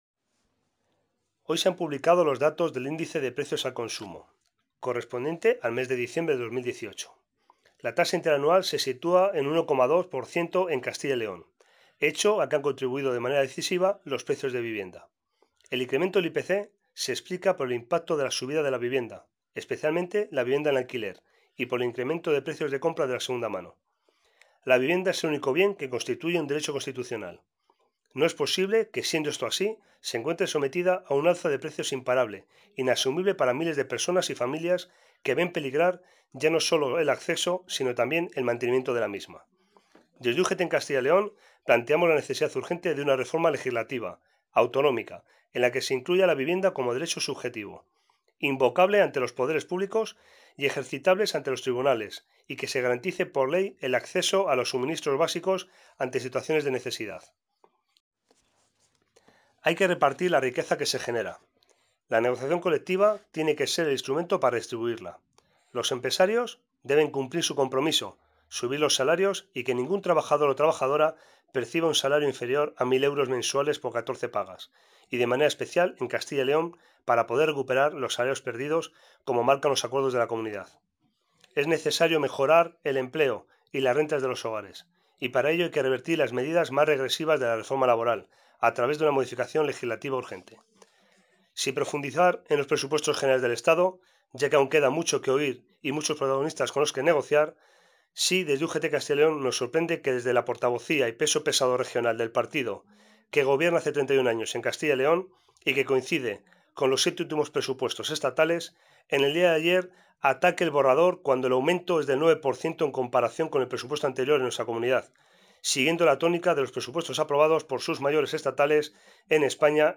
Valoración del Secretario de Política Sindical